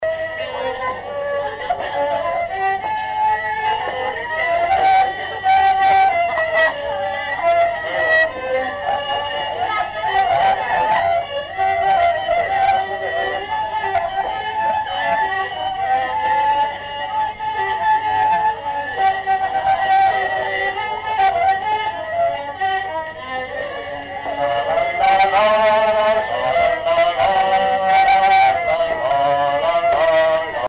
Chants brefs - A danser
danse : valse
Répertoire d'un bal folk par de jeunes musiciens locaux
Pièce musicale inédite